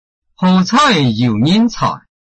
拼音查詢：【饒平腔】coi ~請點選不同聲調拼音聽聽看!(例字漢字部分屬參考性質)